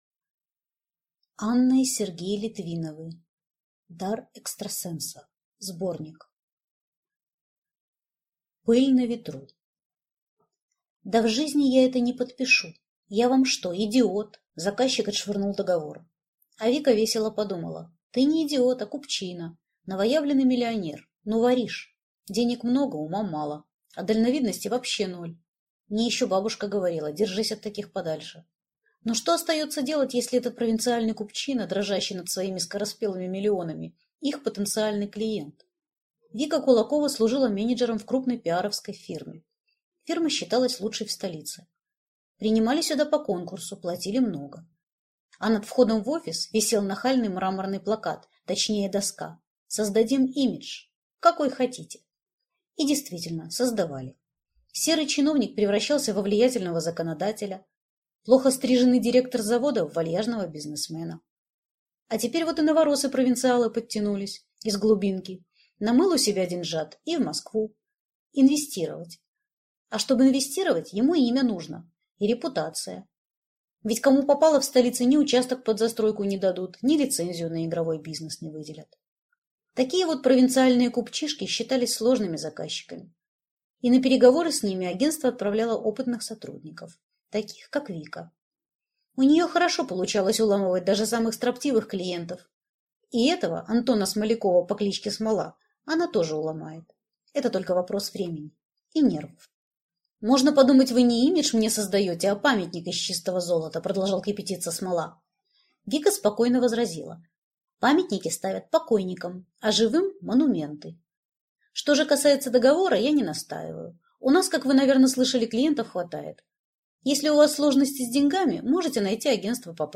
Аудиокнига Дар экстрасенса (сборник) | Библиотека аудиокниг